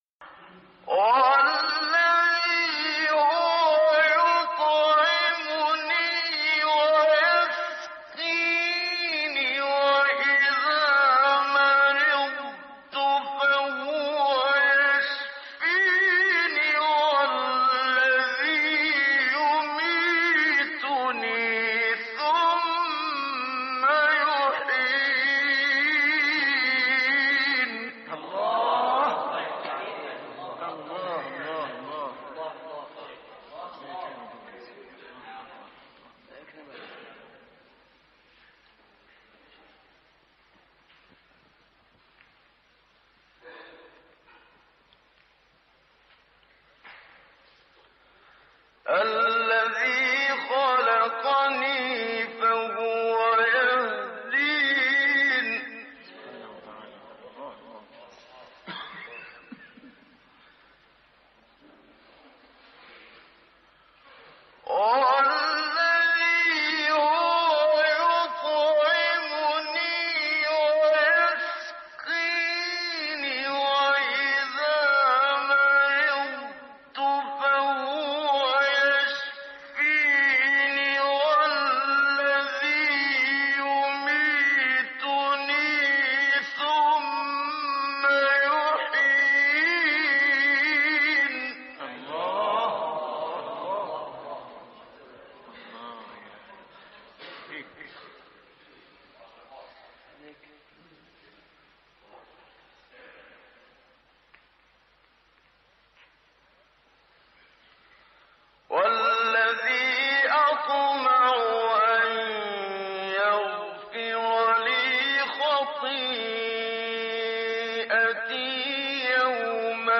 بمقام الرست الشيخ محمد صديق المنشاوي روائع من سورة الشعراء